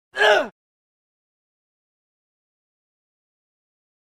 gag1.wav